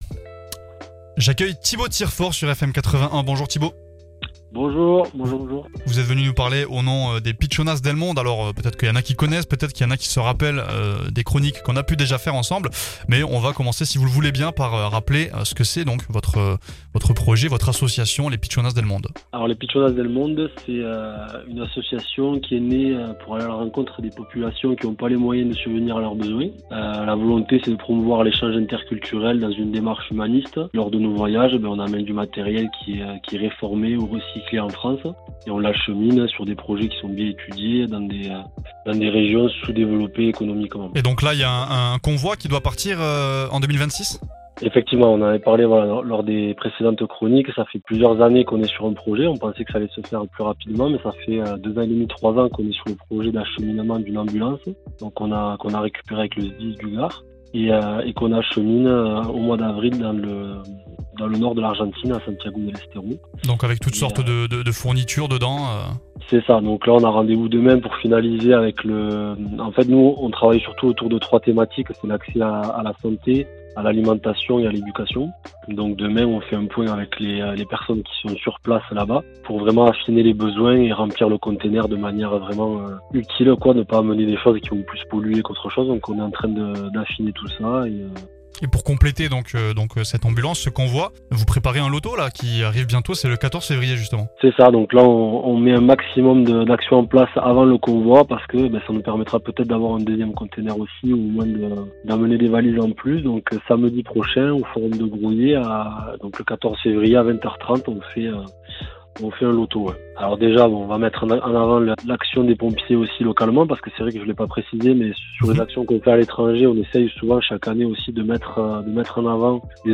Dans ce nouvel épisode de la "Vie locale" , nous recevons